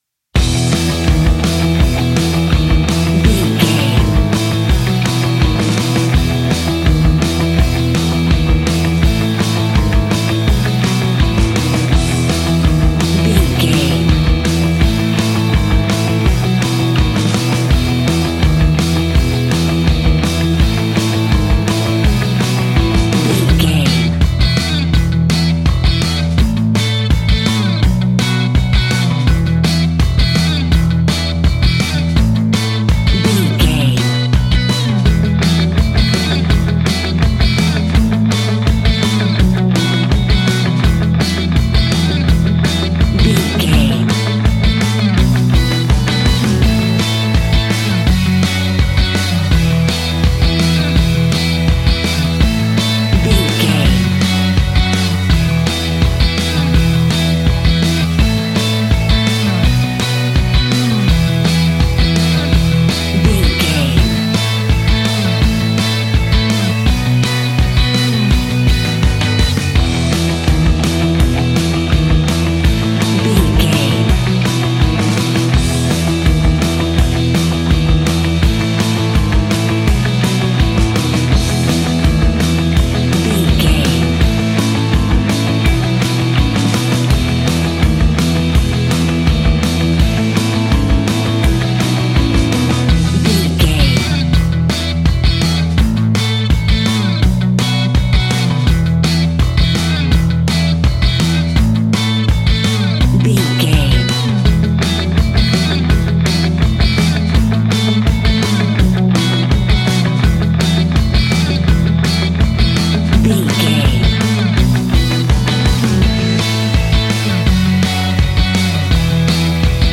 Ionian/Major
pop
pop rock
indie pop
energetic
uplifting
instrumentals
upbeat
uptempo
groovy
guitars
bass
drums
piano
organ